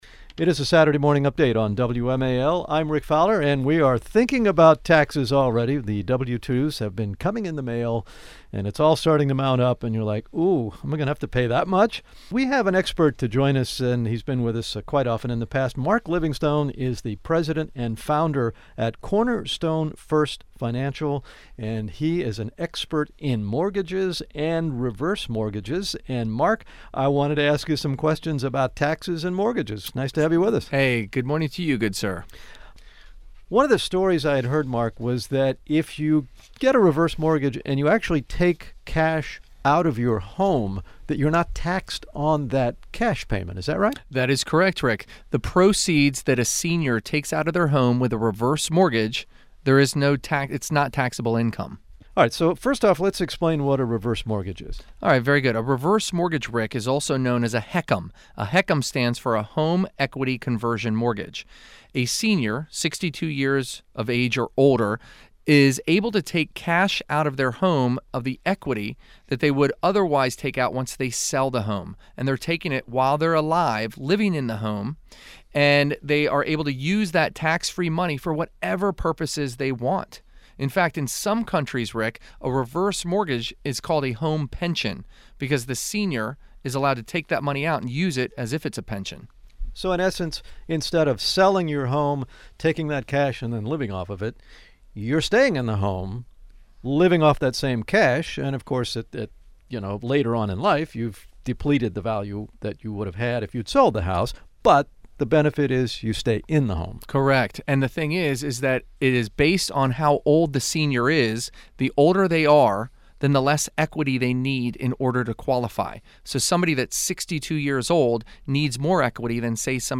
It’s Washington’s way to start your Saturday – on WMAL 105.9 FM & AM 630!